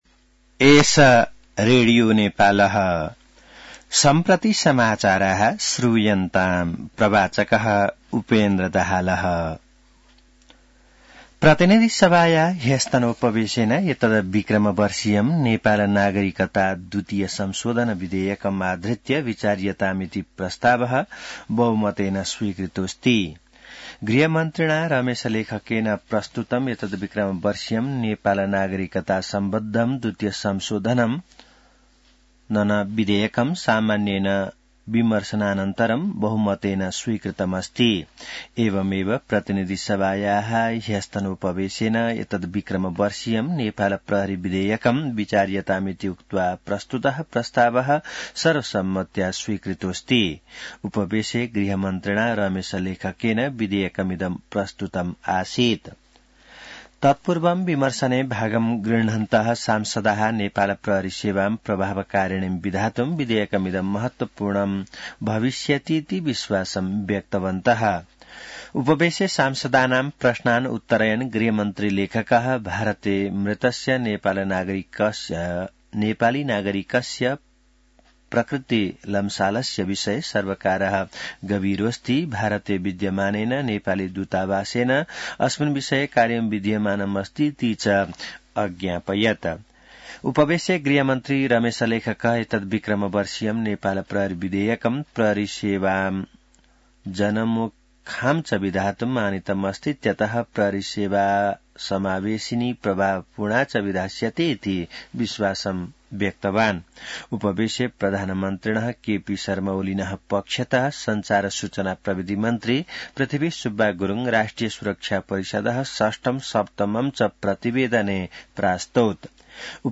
संस्कृत समाचार : ७ फागुन , २०८१